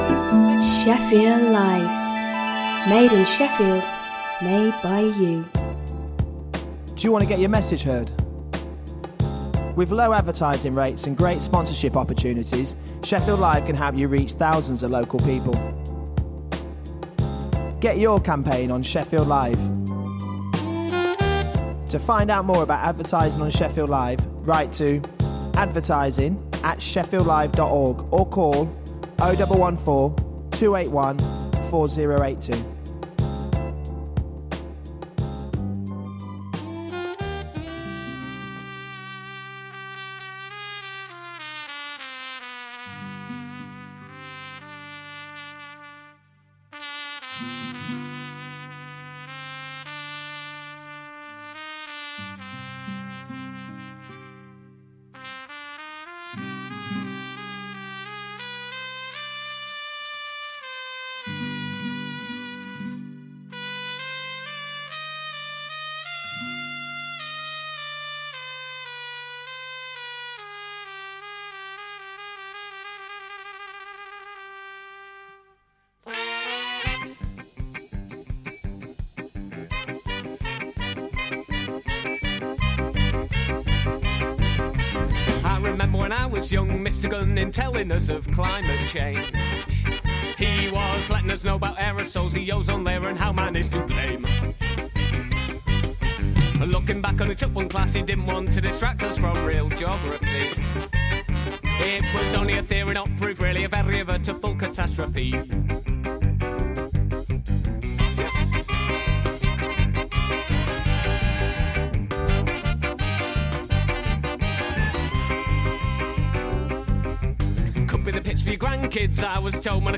Adal Voice of Eritreans is a weekly radio magazine programme for the global Eritrean community.